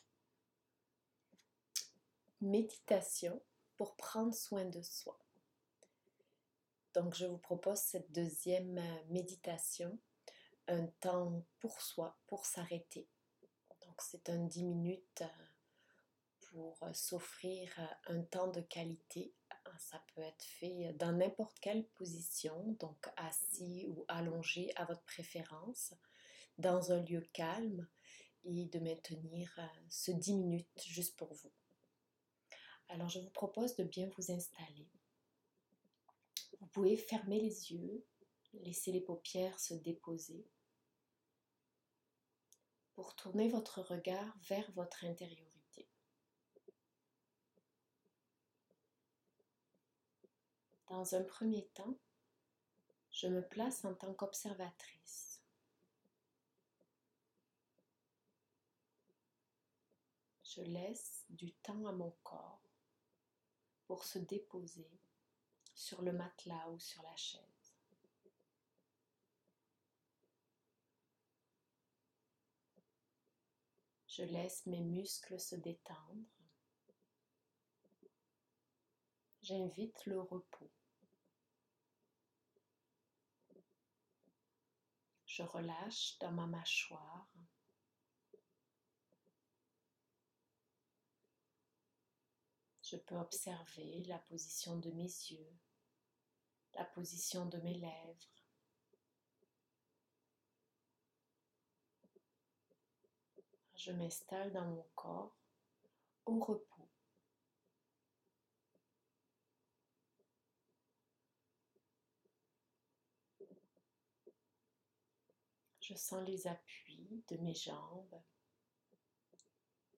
Méditation guidée fait par une psychomotricienne